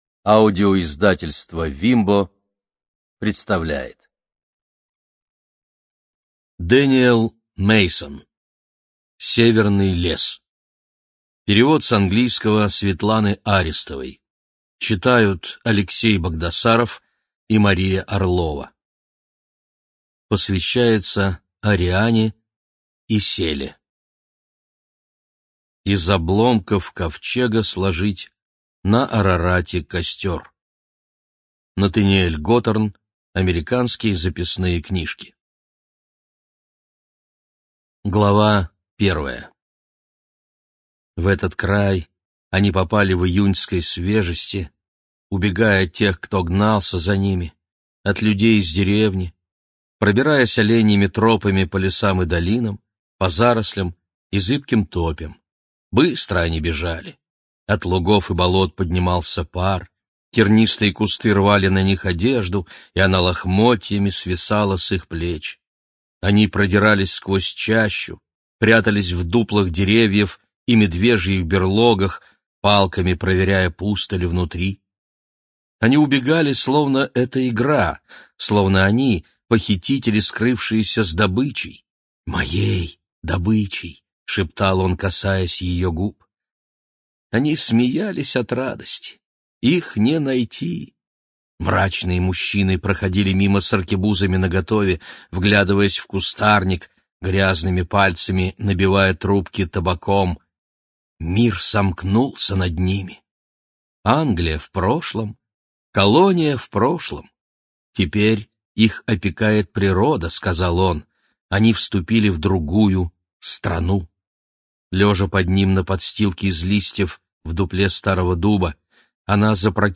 Студия звукозаписивимбо